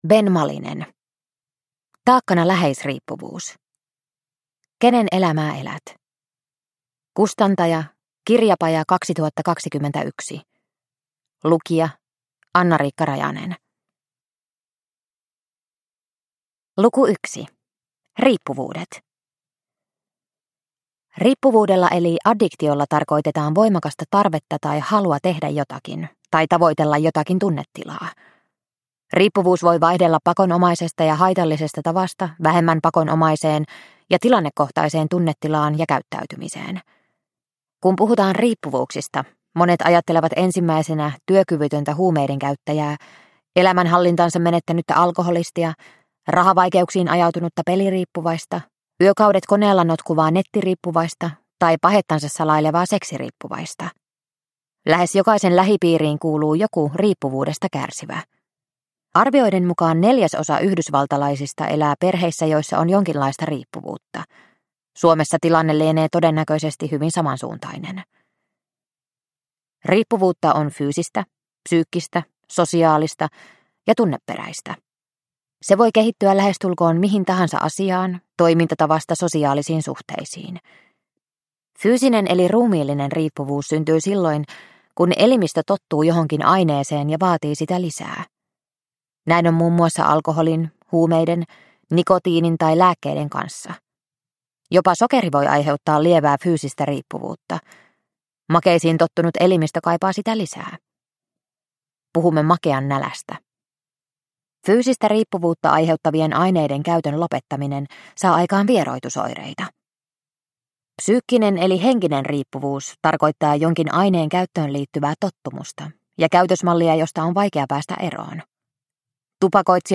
Taakkana läheisriippuvuus – Ljudbok – Laddas ner